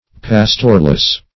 Pastorless \Pas"tor*less\, a. Having no pastor.